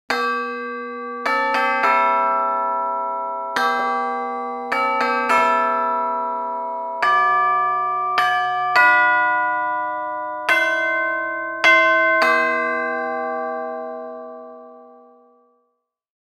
Description: Christmas doorbell chime.
Christmas doorbell melody
Genres: Sound Effects